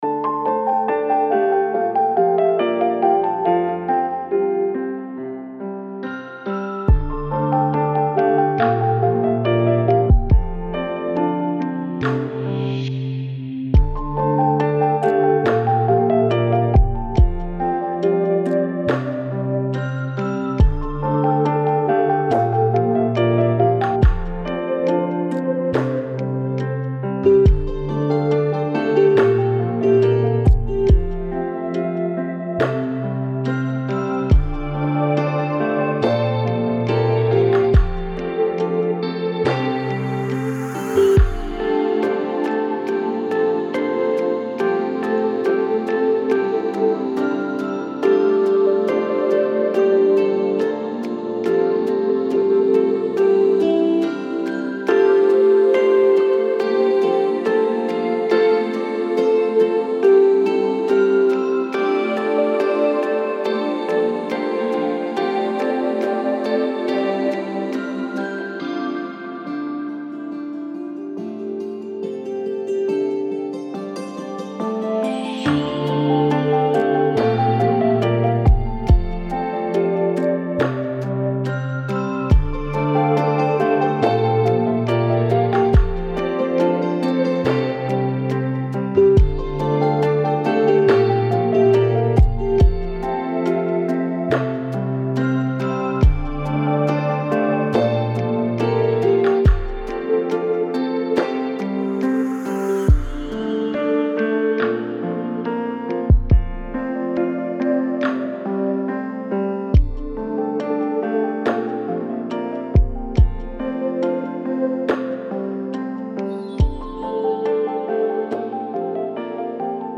Aucun bruit parasite, aucune coupure soudaine.